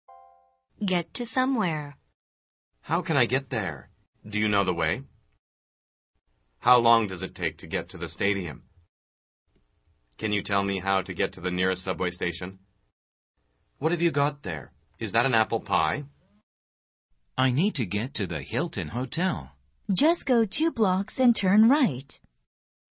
通过生动的对话说明短语的实际表达用法，8000多句最实用的经典表达，保证让你讲出一口流利又通顺的英语，和老外聊天时再也不用担心自己的英语错误百出了！